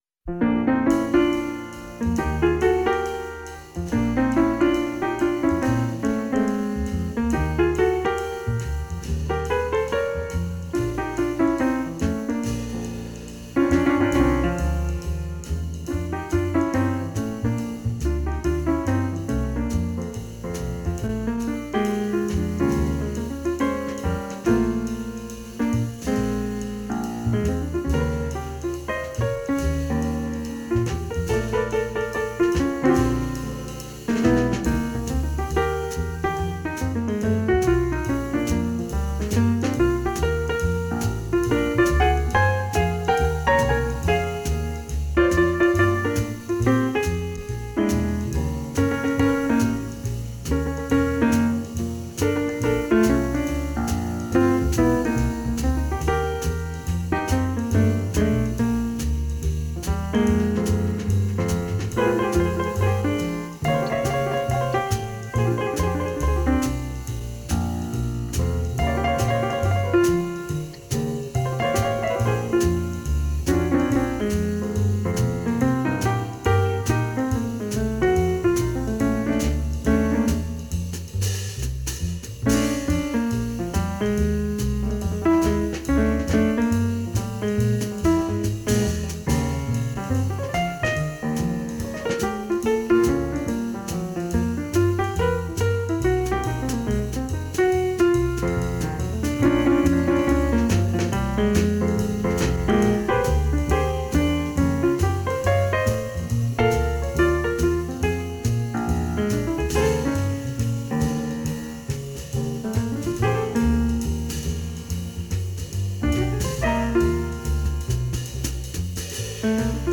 great Jazz classic from a 1963 recording